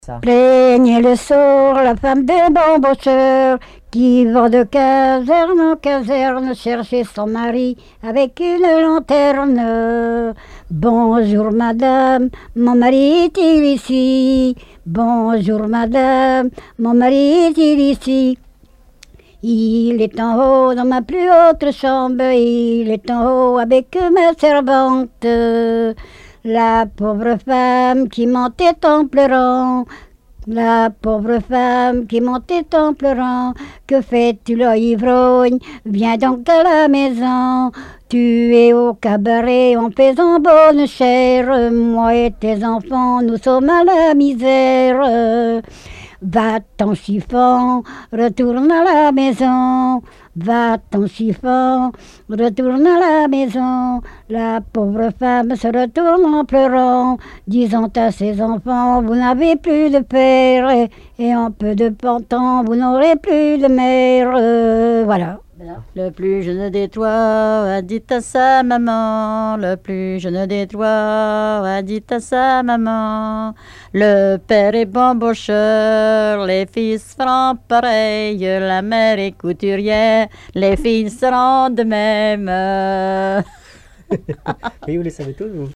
circonstance : bachique
collecte en Vendée
Répertoire de chants brefs et traditionnels
Pièce musicale inédite